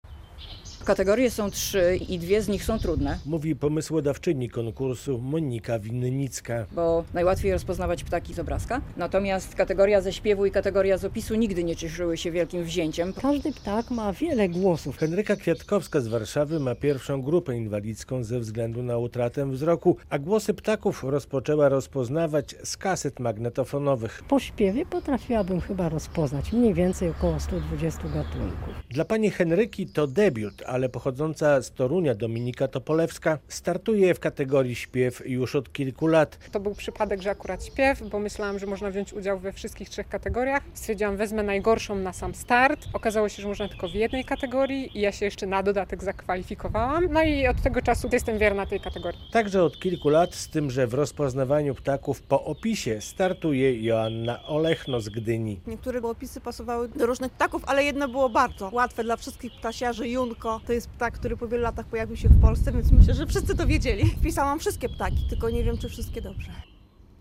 Mistrzostwa Polski w Ptaszków Nazywaniu - relacja